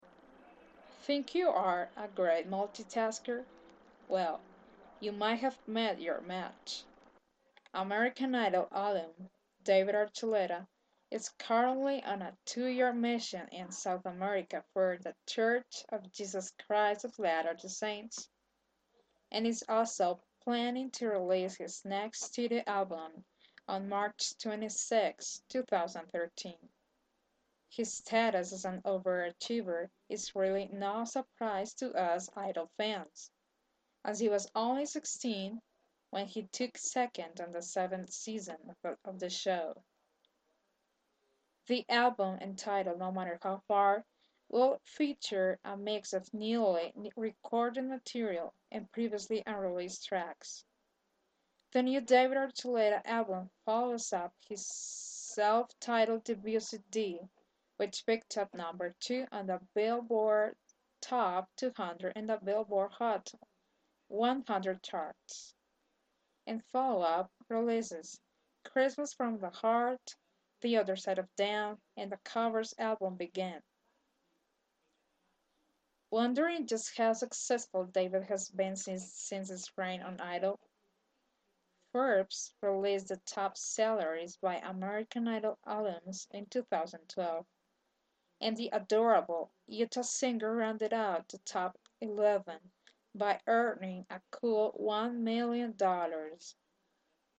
Reading some David Archuleta's news on his released albums so far